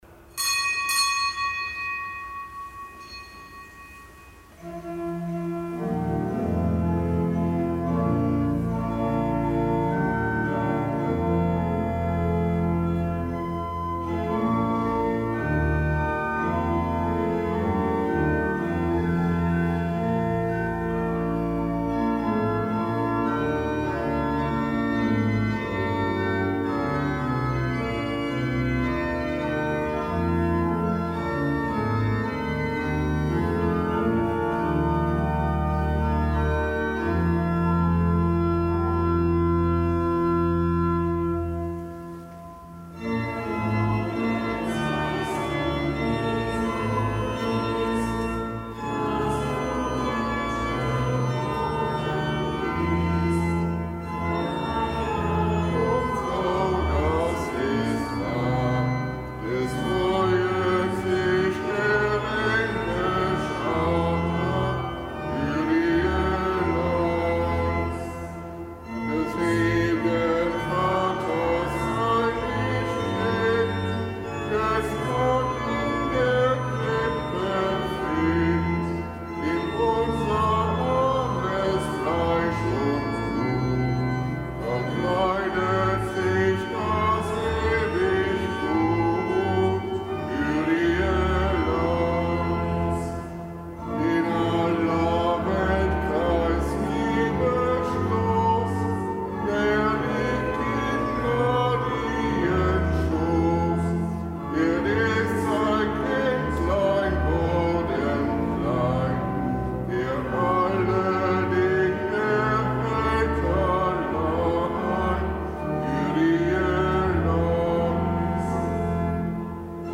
Kapitelsmesse am siebten Tag der Weihnachtsoktav
Kapitelsmesse aus dem Kölner Dom am siebten Tag der Weihnachtsoktav.